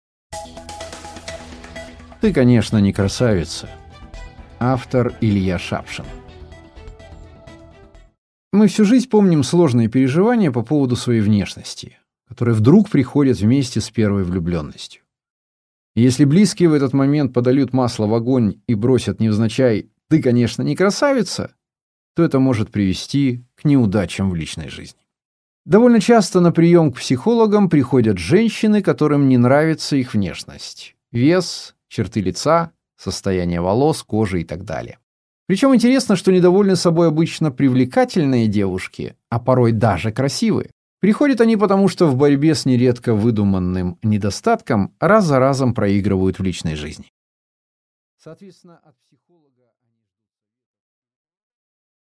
Аудиокнига Ты, конечно, не красавица…